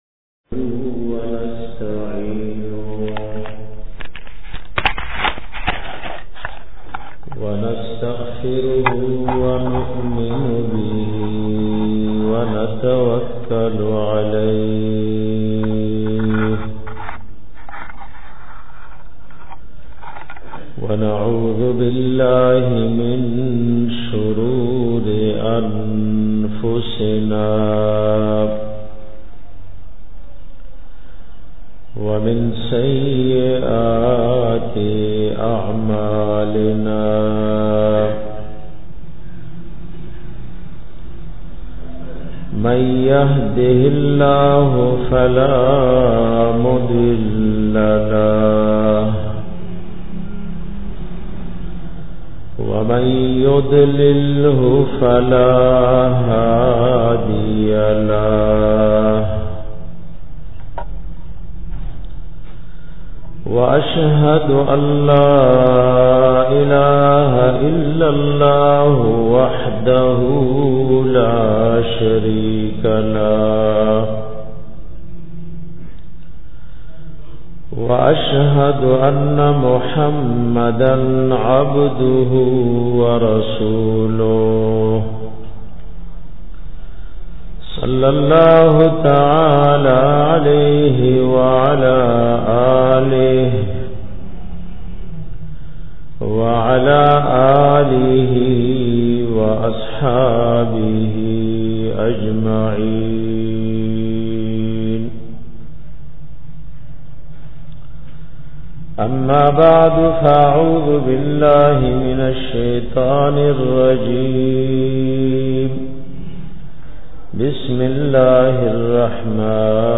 bayan da sheethan na da bachao asbab 2